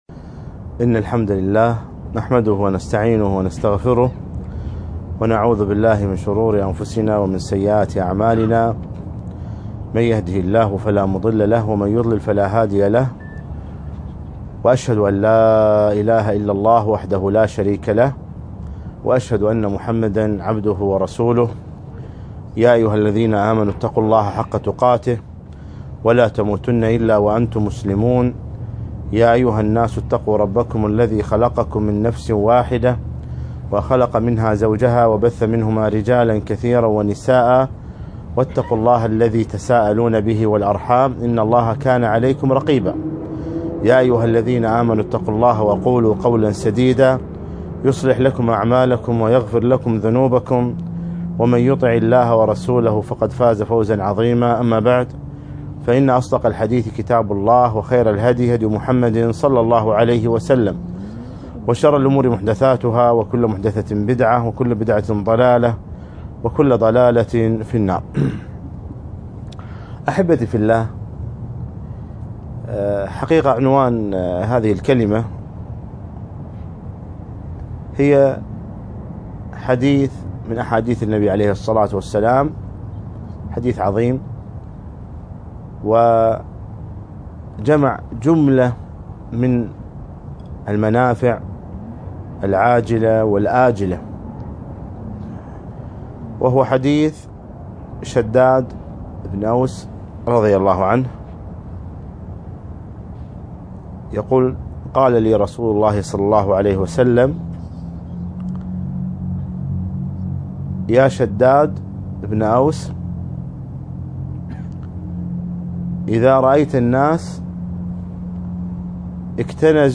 يوم الثلاثاء 8 جمادى الأخر 1438 الموافق 7 3 2017 في ديوانية شباب الرابية الرابية